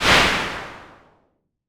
Index of /nodejsapp/foundryvtt/public/sounds/impulse-responses/